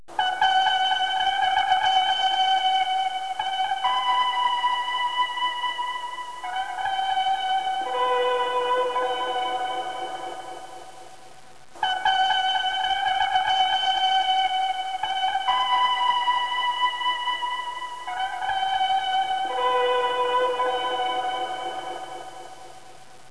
Tromba di Tutankhamon
Questa  tromba d'argento fu scoperta nel 1922 nella tomba del Faraone egiziano Tutankhamon. Fu suonata una sola volta nel 1939 al Cairo neglli studi della BBC e si ruppe.
trombtut.wav